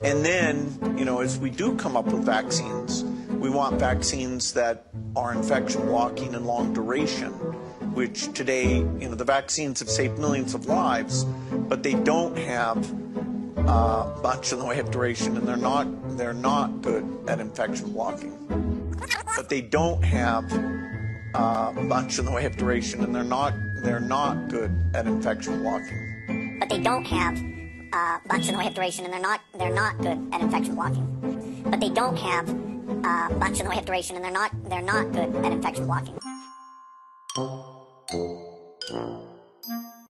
Marketingstratege grösster Geldgeber der WHO, Bill Gates, spricht hier darüber, wie er die Welt mit Impfungen retten will, schön ist er so ehrlich, man höre ihm genau zu: